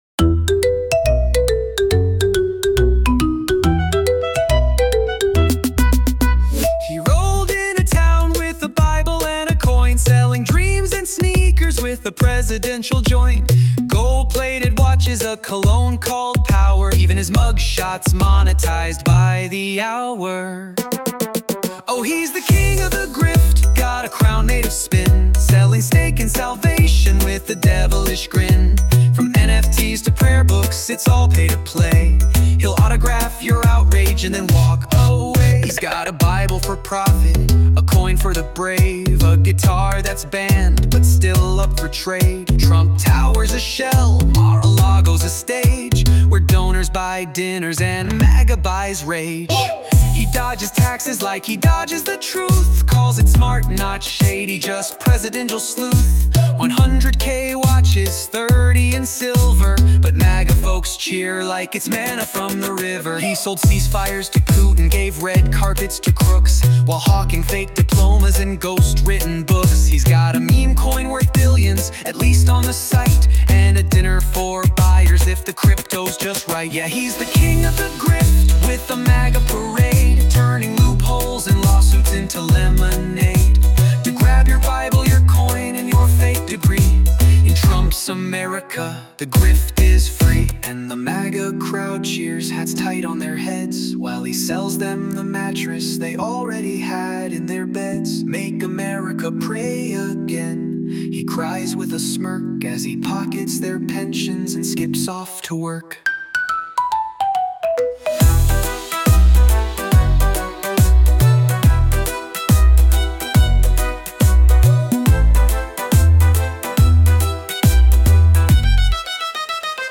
EDM-Good-Beat.mp3